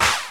Clap
Good Hand Clap G Key 12.wav
modern-clap-one-shot-g-key-18-8yU.wav